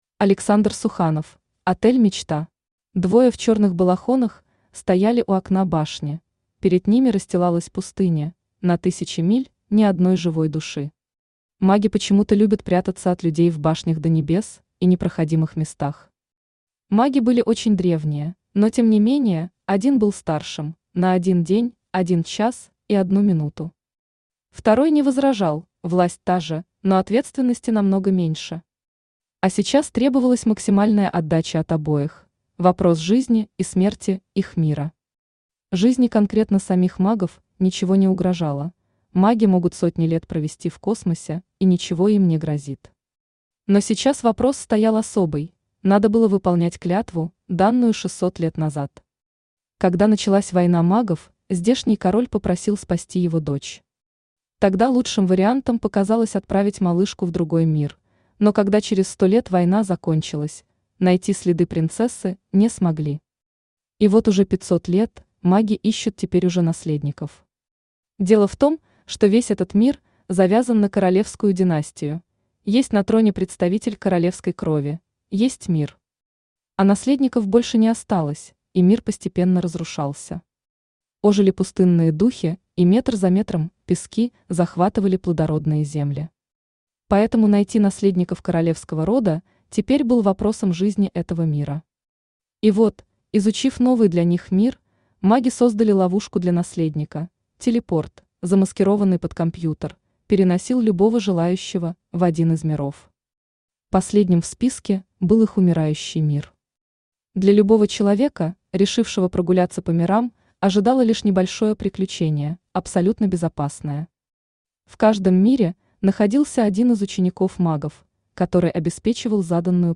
Аудиокнига Отель Мечта | Библиотека аудиокниг
Aудиокнига Отель Мечта Автор Александр Суханов Читает аудиокнигу Авточтец ЛитРес.